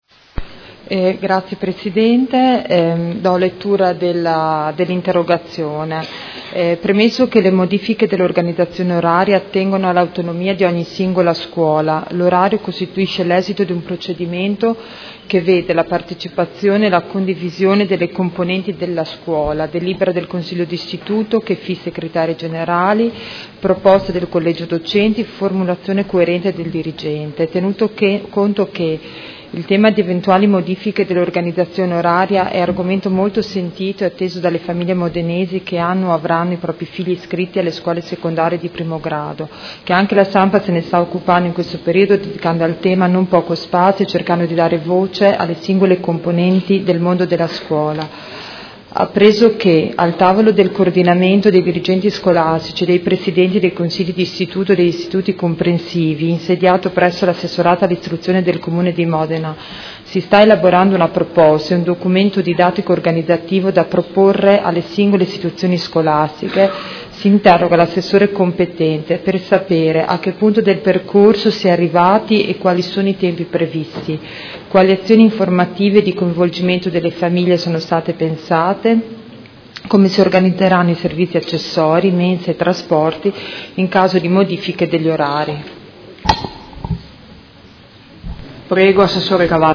Seduta del 14/12/2017 Interrogazione dei Consiglieri Baracchi e Carpentieri (PD) avente per oggetto: Organizzazione oraria scuole secondarie di primo grado